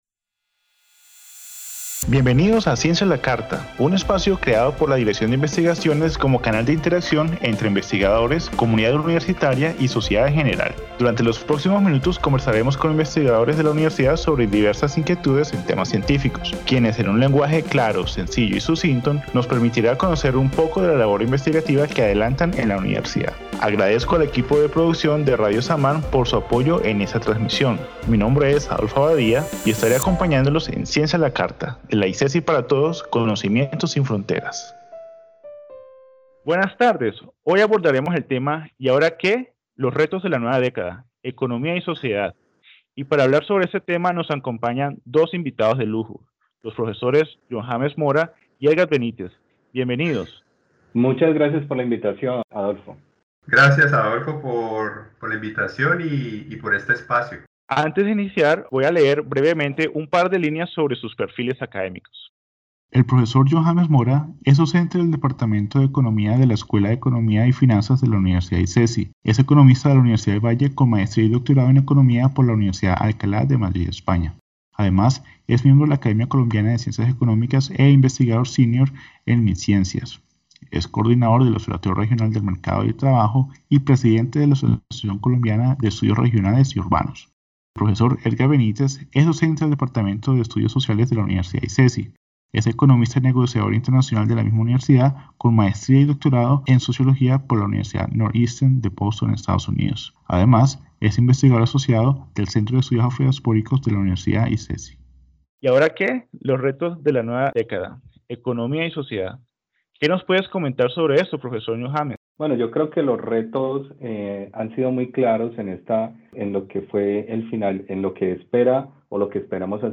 En el primer momento del programa, los investigadores invitados tendrán un espacio para reaccionar, libremente, al tema del día y, posteriormente, se traerán a la mesa preguntas formuladas previamente por el público para ser abordadas por medio del diálogo con los expertos invitados.